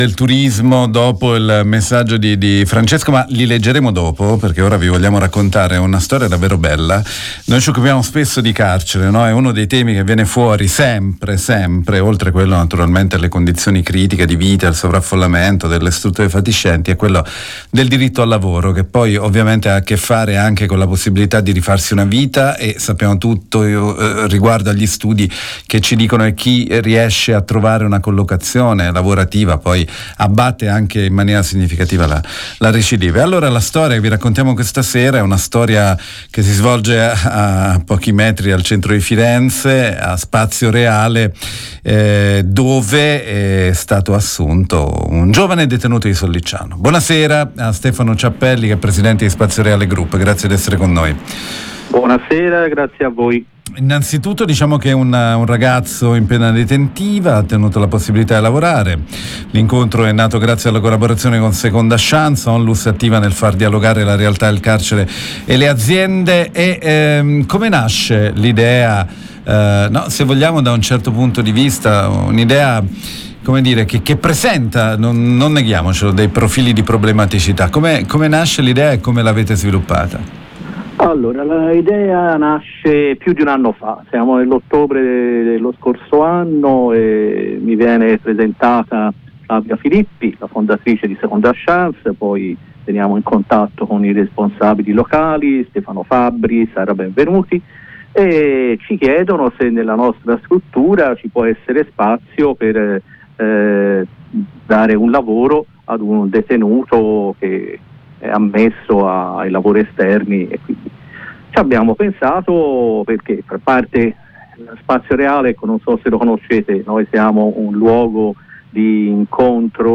L’incontro è nato grazie alla collaborazione con Seconda Chance, onlus attiva nel far dialogare la realtà del carcere e le aziende. Intervista